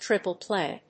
アクセントtríple pláy
音節trìple pláy